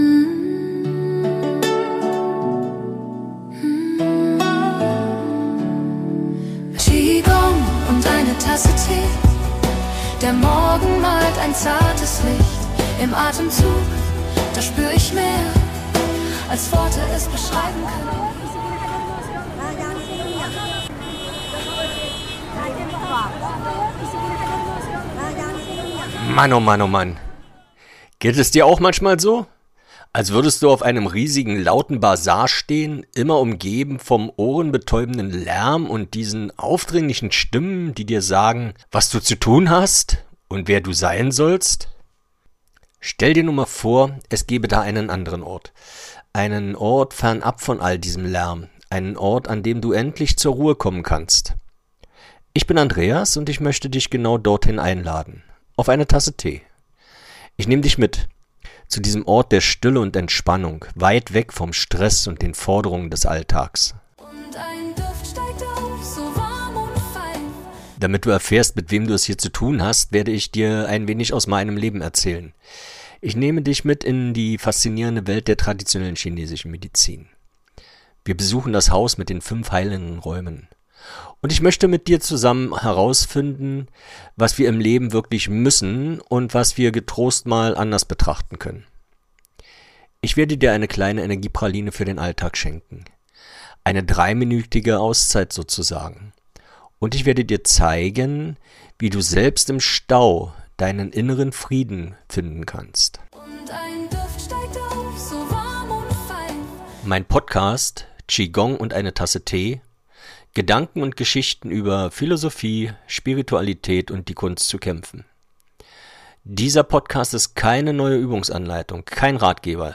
Trailer
Mit Musik, die atmet, und Worten, die Raum